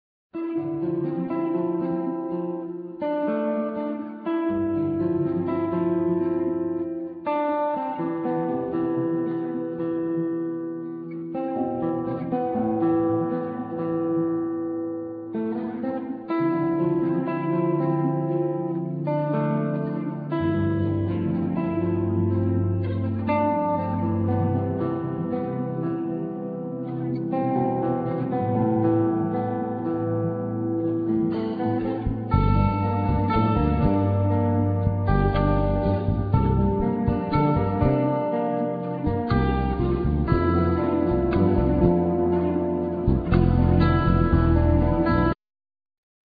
Piano,Synthe Solo
Lyricon
Bandoneon
Ney
Drums
Bass
Percussion,Vocal